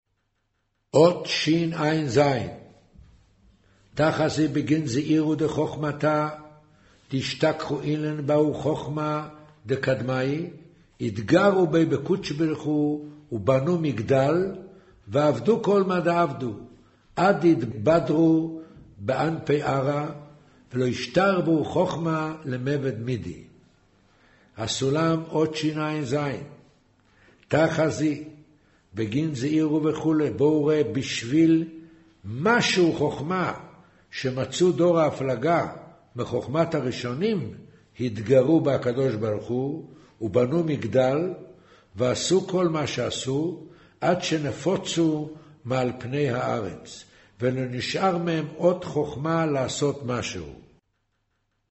אודיו - קריינות זהר, פרשת נח, מאמר ויאמר ה' הן עם אחד